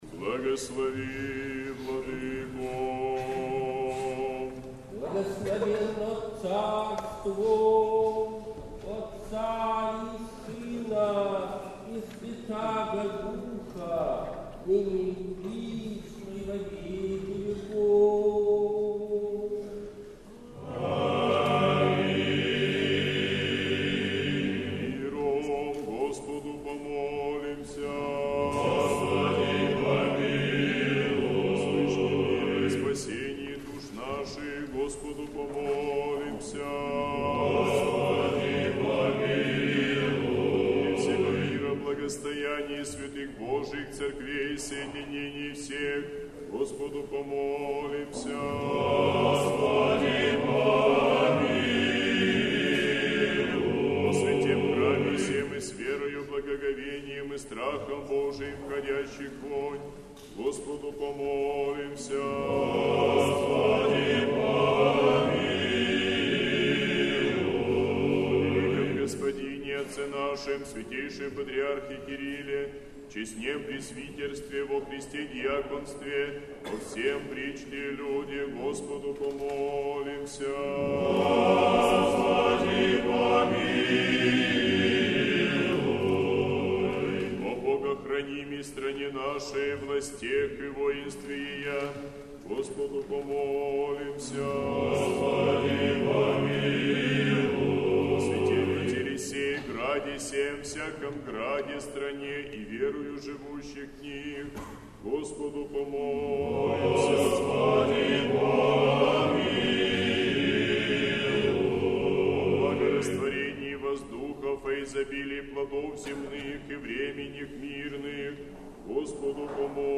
Божественная литургия в Неделю 14-ю по Пятидесятнице в Сретенском монастыре
Божественная литургия. Хор Сретенского монастыря.